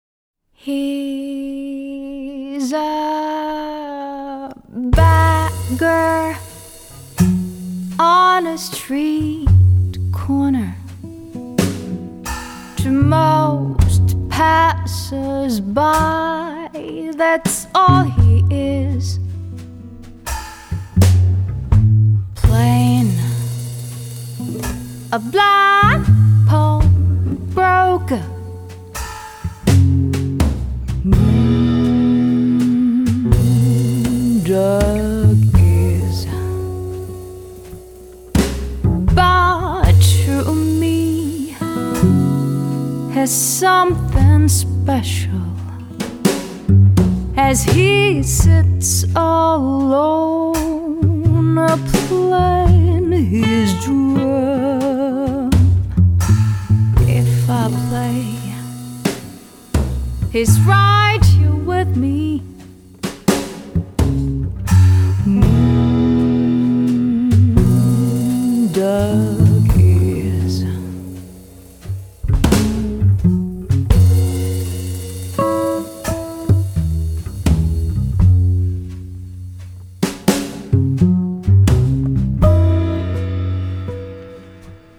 -   爵士及藍調 (298)
絕美的發燒女聲示範盤